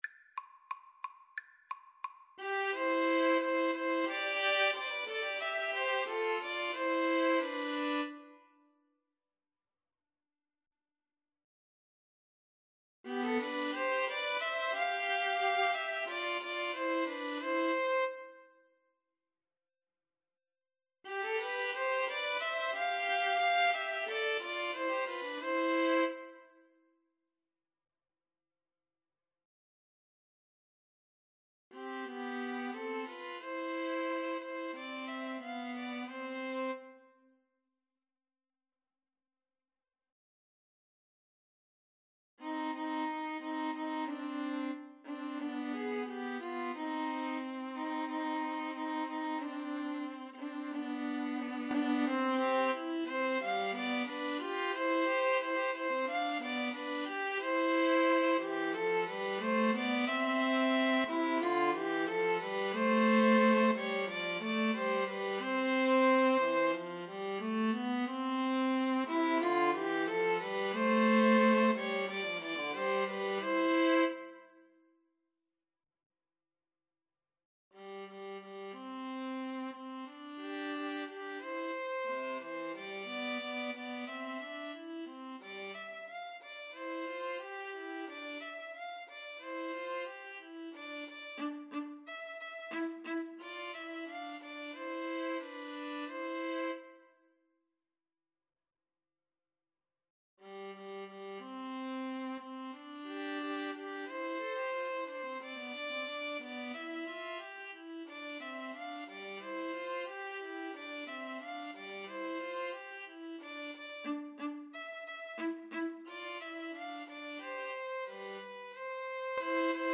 ViolinViolaPiano
4/4 (View more 4/4 Music)
Allegro =180 (View more music marked Allegro)
Classical (View more Classical Piano Trio Music)